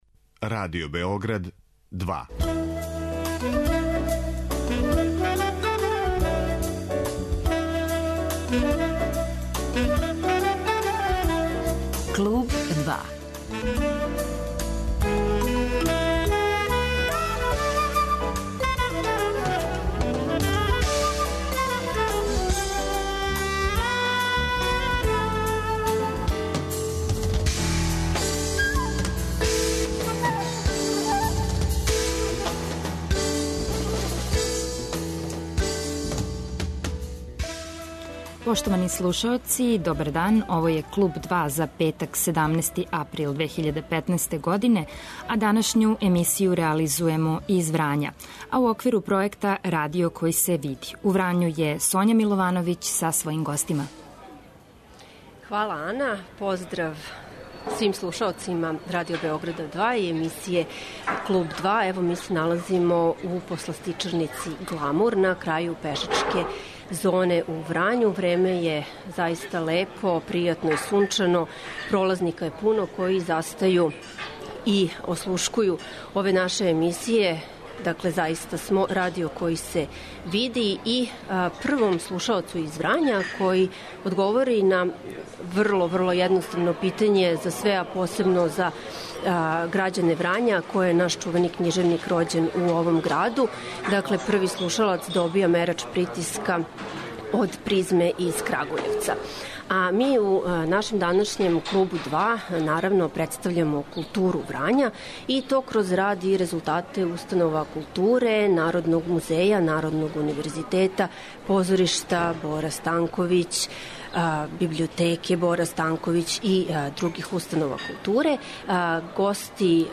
У 'Клубу 2', који емитујемо из Врања, имаћете прилику да чујете како изгледа култура овога краја Србије.
Наши гости, директори и представници културних установа, дочараће нам Музеј кућу Боре Станковића, позориште и библиотеку који носе име тог значајног књижевника, чијом заслугом су Врање и врањански крај постали повлашћено место у српској књижевности.